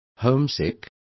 Complete with pronunciation of the translation of homesick.